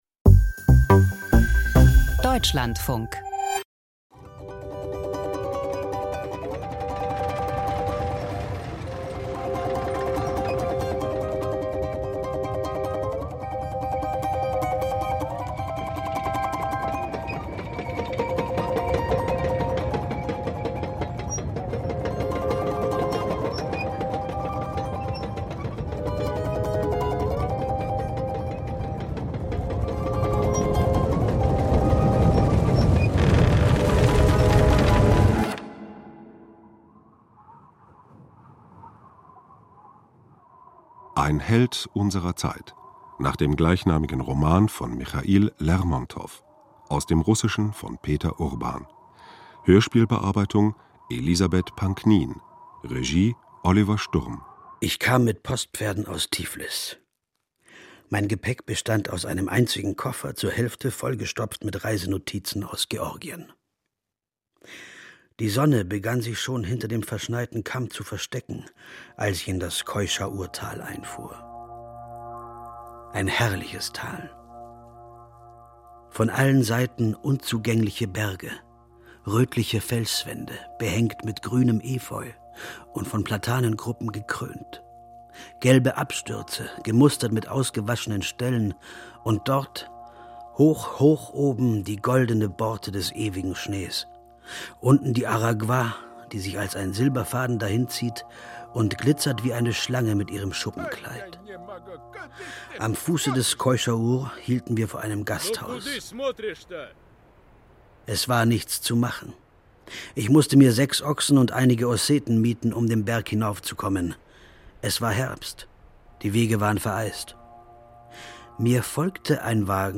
Hörspiel - Ein Held unserer Zeit